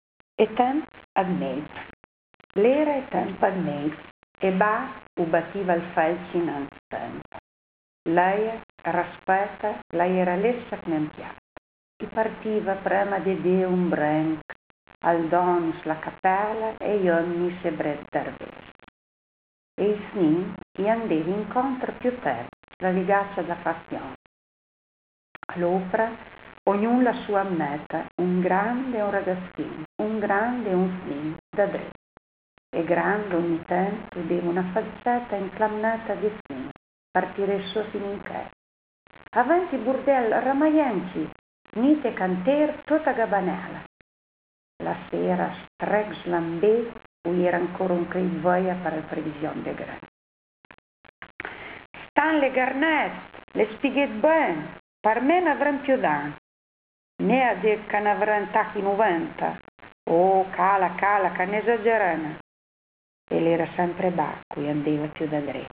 Lettura registrata a Mercato Saraceno il 15 febbraio 2011.